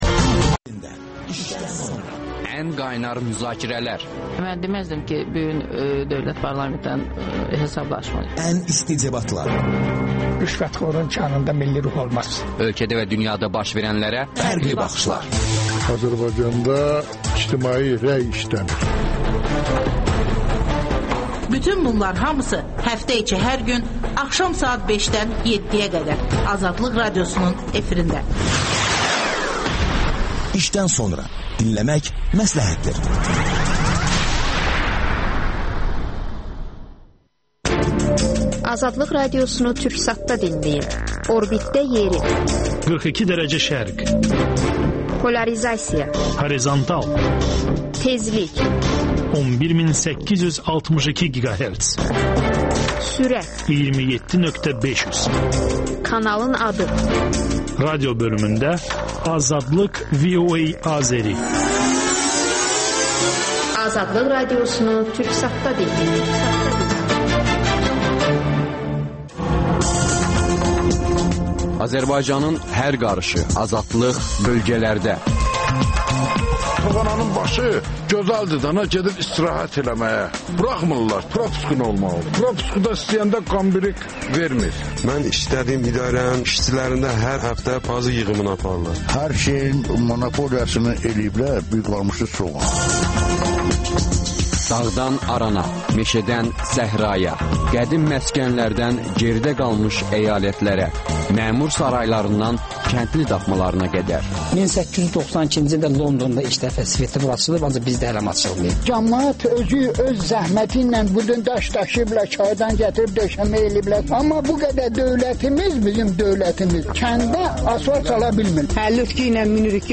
Politoloq